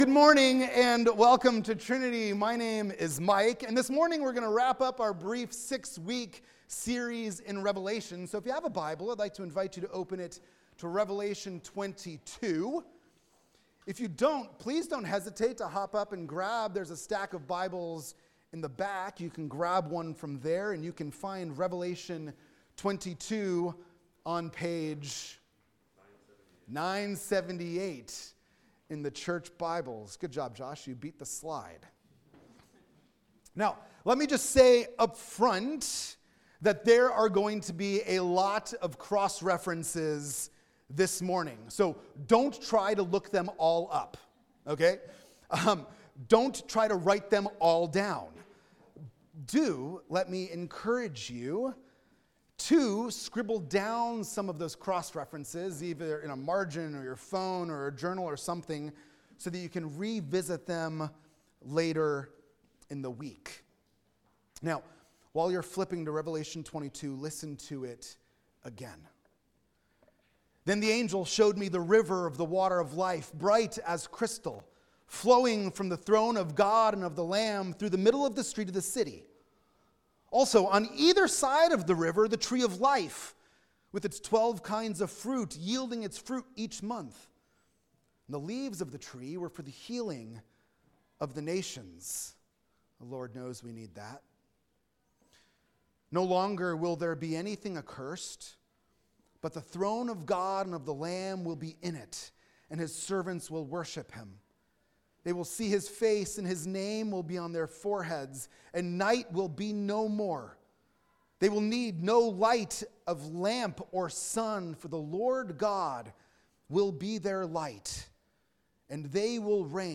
In this Christmas Eve sermon